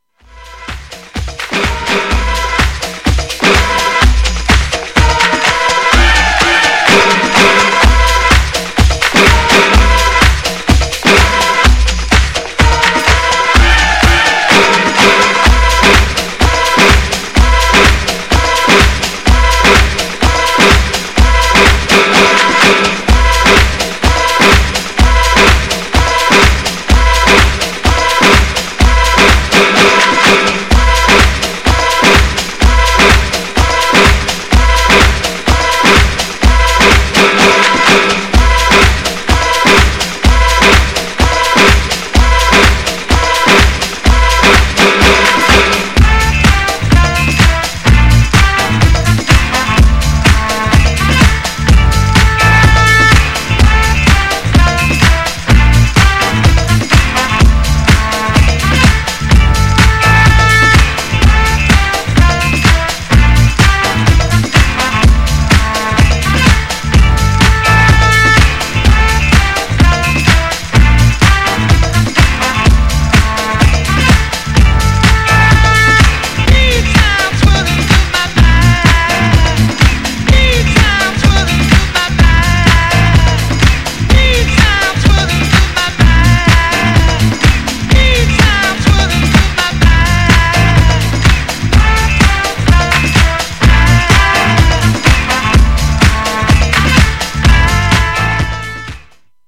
GENRE House
BPM 121〜125BPM
DISCO_HOUSE
トライバル # パーカッシブ